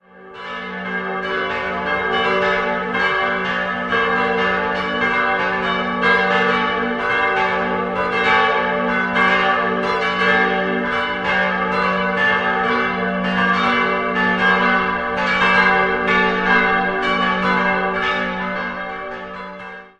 4-stimmiges Geläute: g'-a'-h'-d'' Die kleine Glocke wurde 1954 von Georg Hofweber in Regensburg gegossen, die große und die zweitkleinste bereits 1949 von der Gießerei Petit&Edelbrock in Gescher (Westfalen).